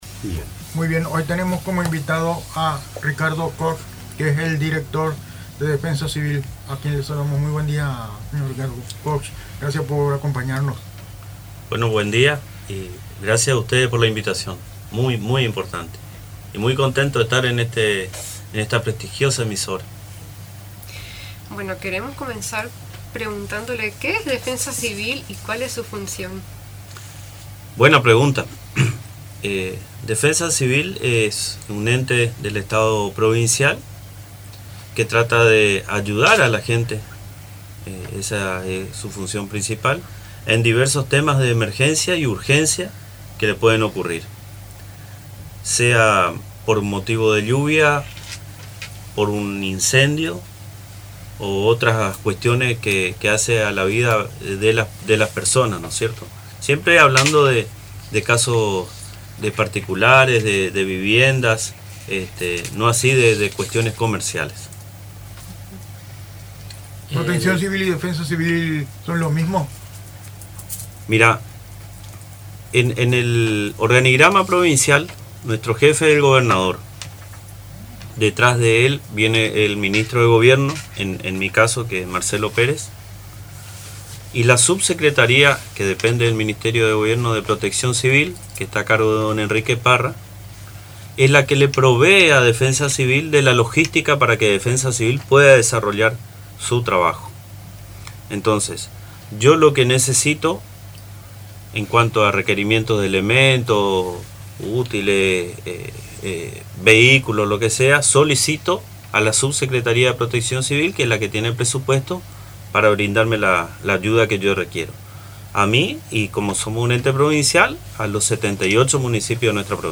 Durante una entrevista amena en el programa de radio "Nos vemos" del Centro del Ciego de Posadas, transmitido por Radio Tupa Mbae, Ricardo Koch, Director de Defensa Civil, compartió detalles sobre las funciones y el trabajo de este organismo estatal provincial.